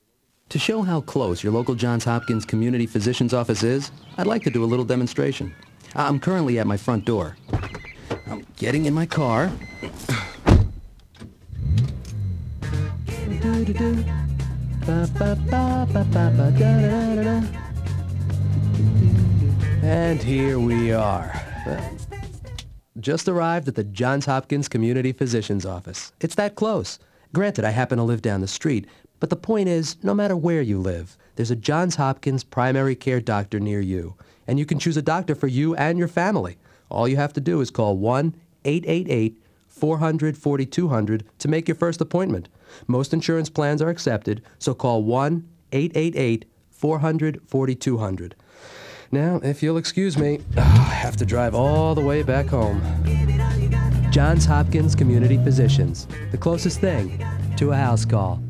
VOICE OVERS Television and Radio
RADIO